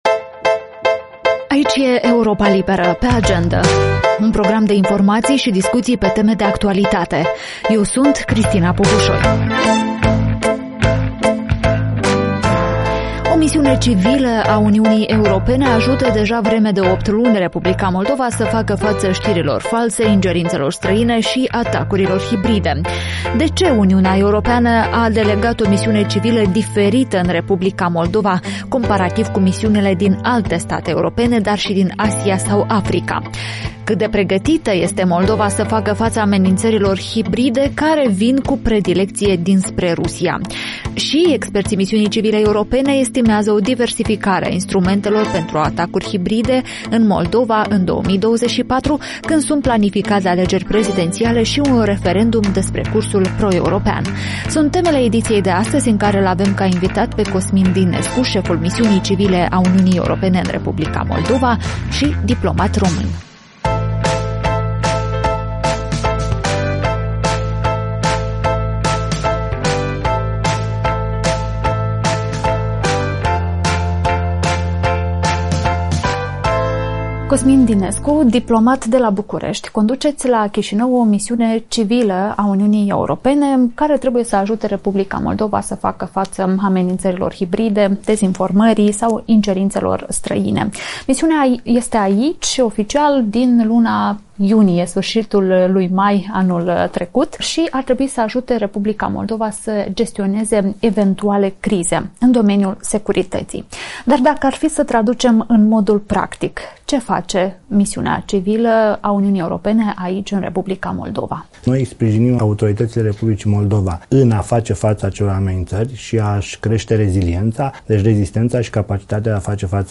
Șeful misiunii civile a UE în R. Moldova, Cosmin Dinescu vorbește în podcastul video al Europei Libere „Pe agendă” despre colaborarea cu SIS-ul și alte instituții responsabile de securitatea cibernetică, gestionarea crizelor și amenințărilor hibride.